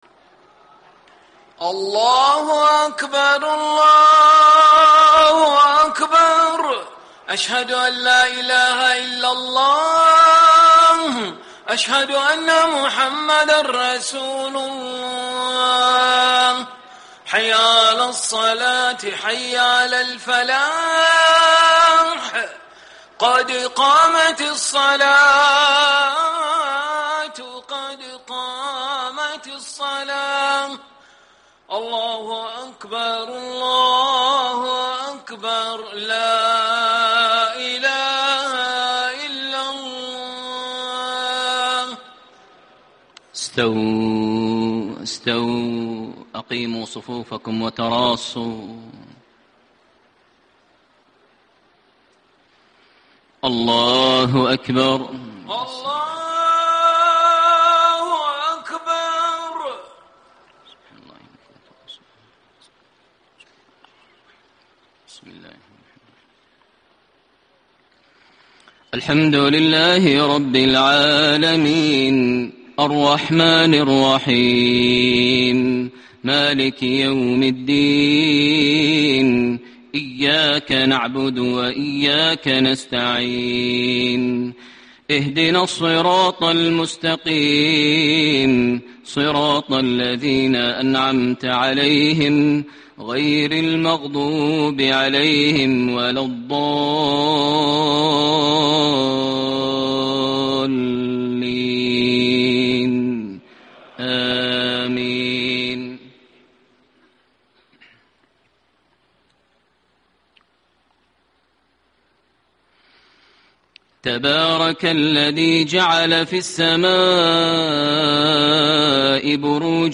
صلاة العشاء 3-6-1435 ما تيسر من سورة الفرقان > 1435 🕋 > الفروض - تلاوات الحرمين